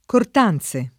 [ kort # n Z e ]